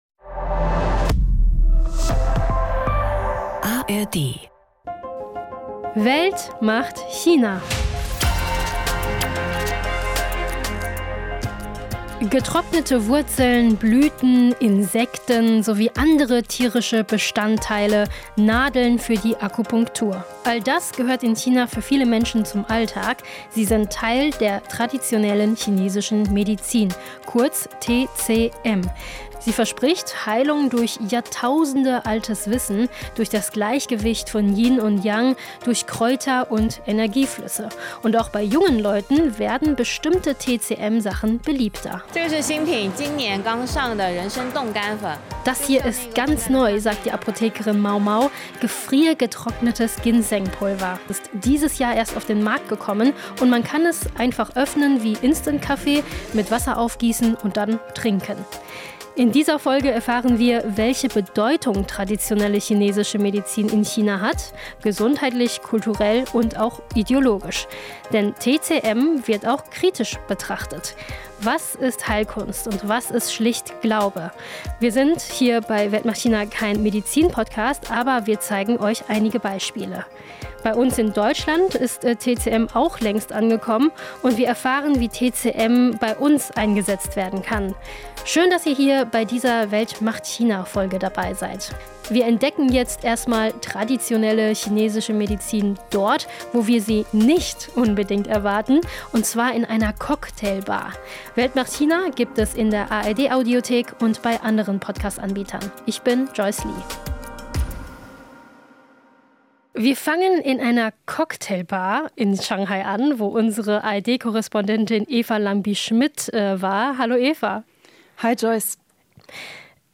Aktuelle und ehemalige Korrespondent*innen und Expert*innen haben sich zusammengetan, um einen vielfältigen Einblick zu geben in das riesige Land.